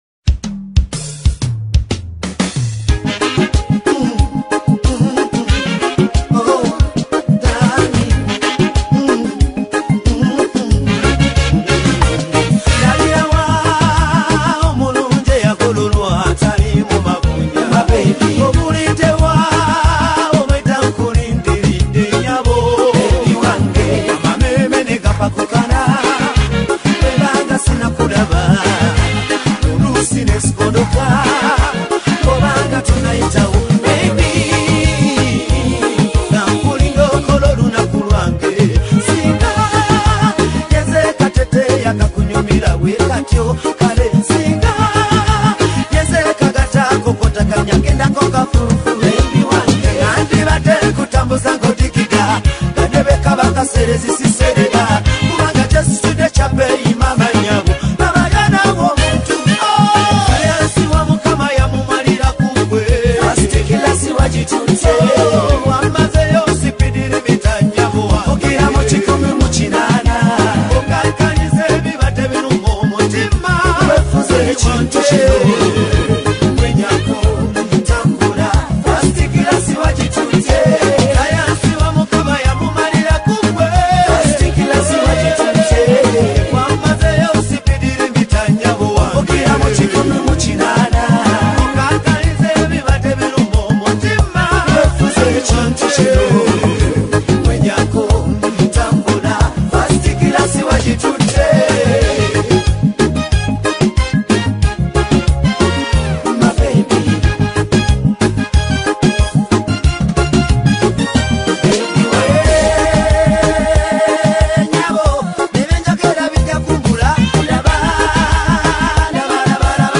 delivers a bold and energetic performance
a powerful anthem of self-belief and elevation